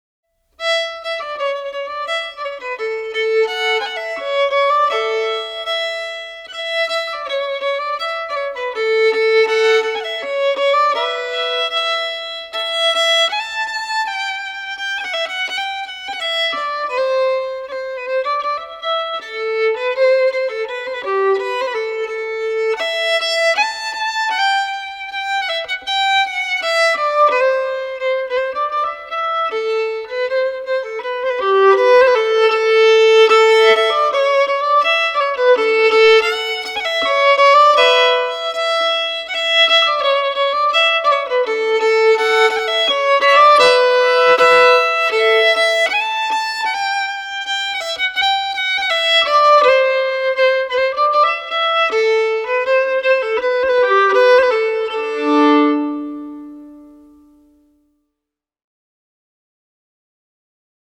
Solo violin tracks recorded at FTM Studio in Denver Colorado
Danish Folk Song
Violin Solo Danish.mp3